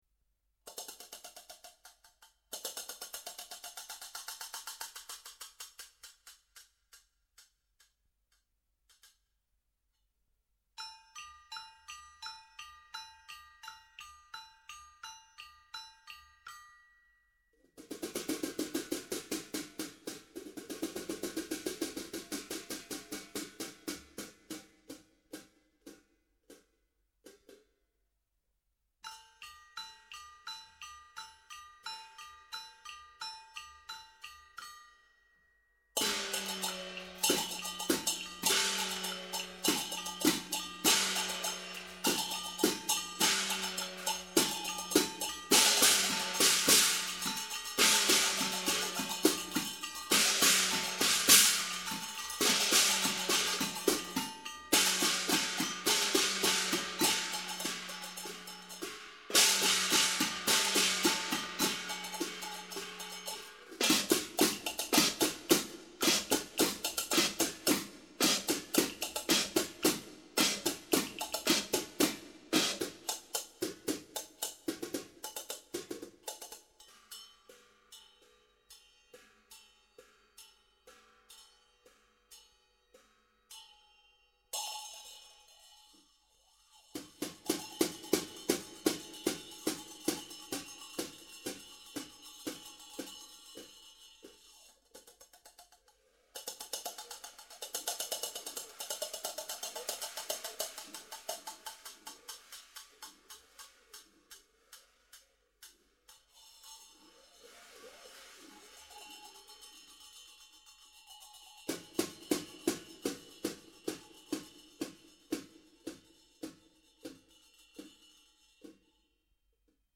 打击乐小合奏
声音模仿的很象鸭子，谢谢您无私分享好音乐！